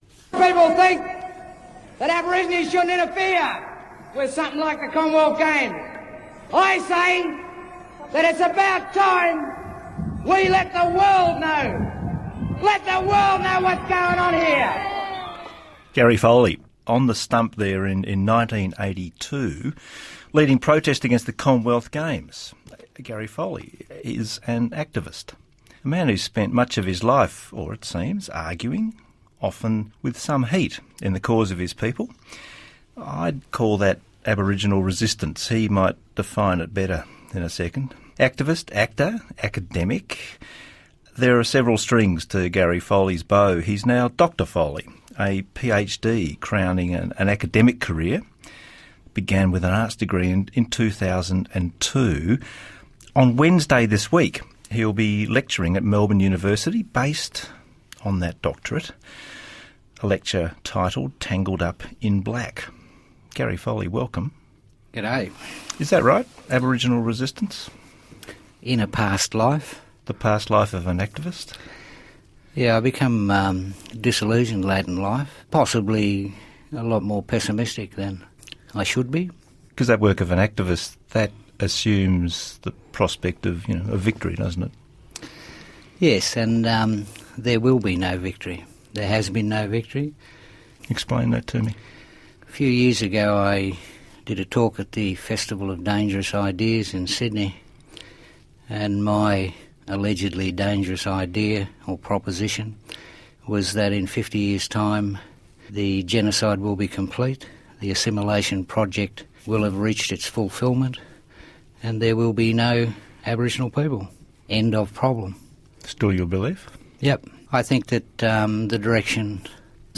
In a lead up to his 2014 Melbourne University lecture, 'Tangled up in black' he speaks with Jonathan Green from ABC's Radio National.